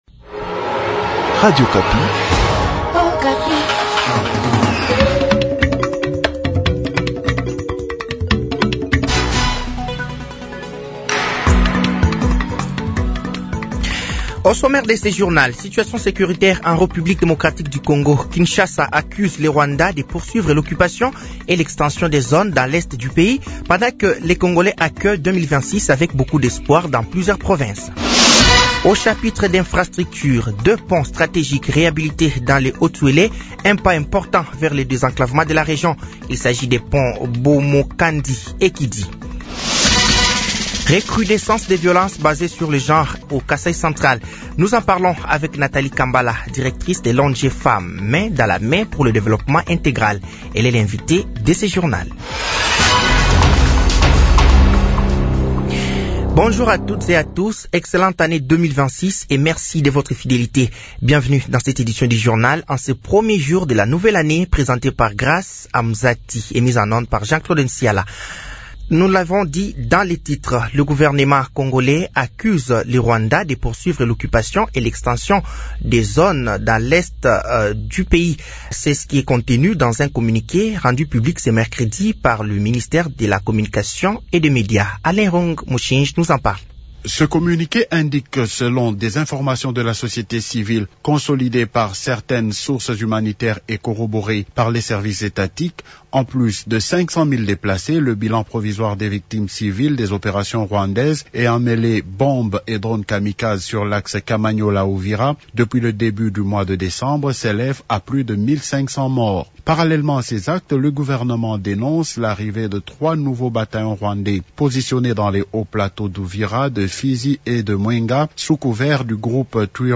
Journal français de 12h de ce jeudi 1er janvier 2026